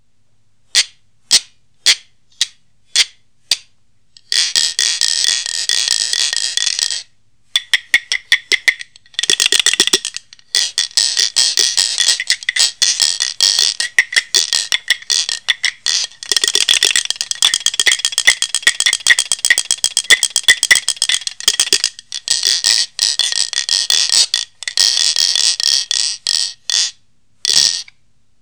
4.2.2.1.CẶP KÈ hay SÊNH SỨA
ặp Kè tiếng trong, dòn, vui tươi, có những tiếng rung rất độc đáo.